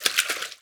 fishsplash.wav